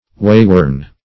Wayworn \Way"worn`\, a.